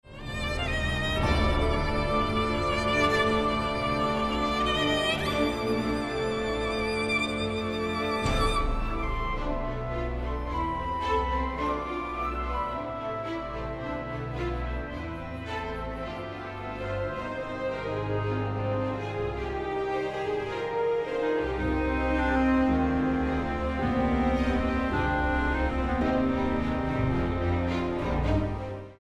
Genre: Classical / Halloween
for Solo Viola and Orchestra
Solo Viola and Solo Violin played and recorded by
Virtual Orchestra produced